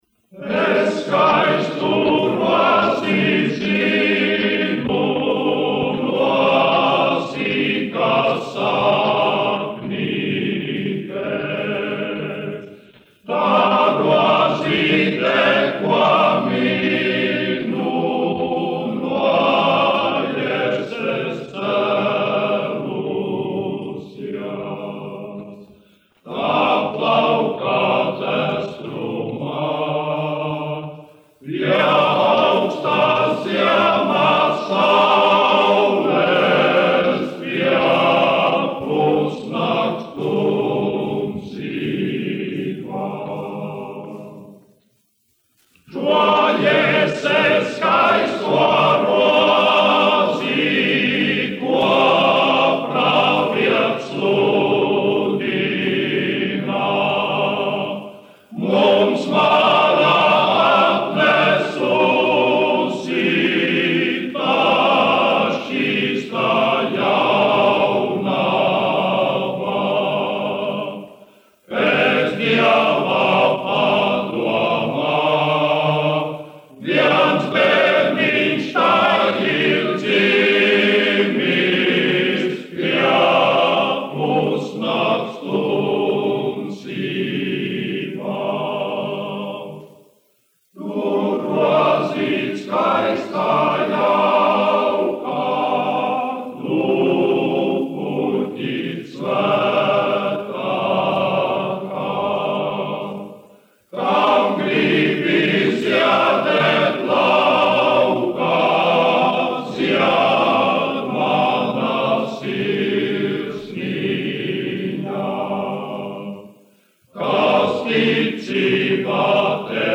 Ņujorkas Daugavas Vanagu vīru koris, izpildītājs
1 skpl. : analogs, 78 apgr/min, mono ; 25 cm
Ziemassvētku mūzika
Kori (vīru)
Latvijas vēsturiskie šellaka skaņuplašu ieraksti (Kolekcija)